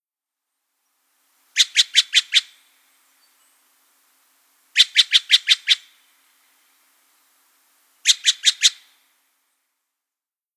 Steller's Jay
Did you know? Steller’s Jays can imitate the sounds of squirrels, cats, dogs, chickens, and even some mechanical objects. How they sound: These Jays have a song of lilting chips , but as excellent mimics, their calls can often sound like a mix of many other species.